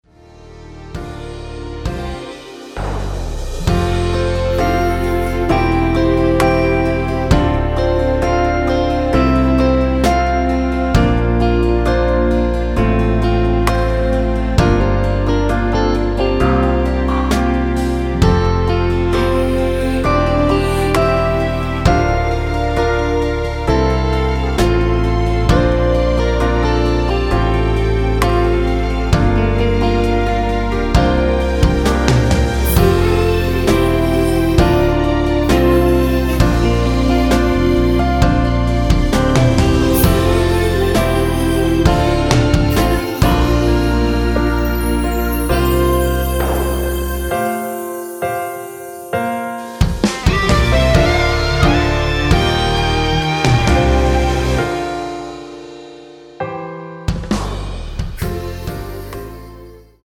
원키에서(+4)올린 코러스 포함된 MR입니다.(미리듣기 확인)